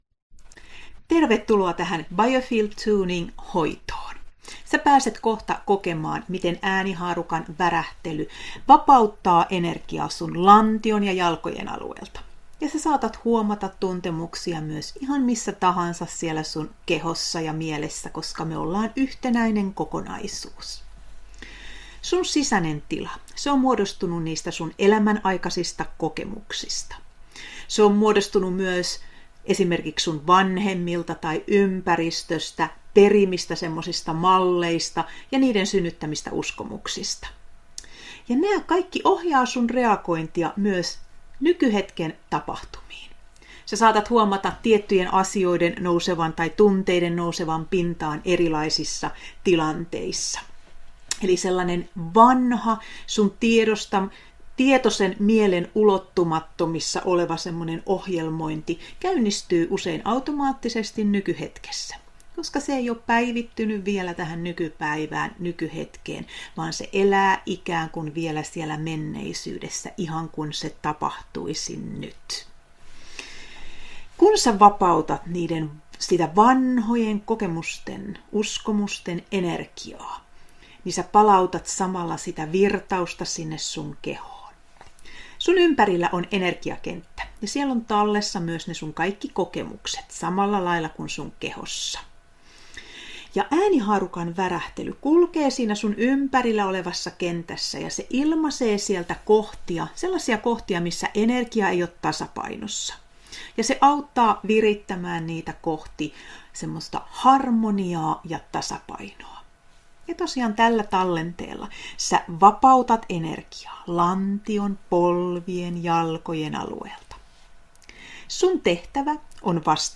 Klikkaa, valitse kolme pistettä ja lataa Biofeld Tuning äänite 43 min Alla olevalla Biofield Tuning äänitteellä äänihaarukan värähtely vapauttaa energiaa mm. lantion ja jalkojen alueelta.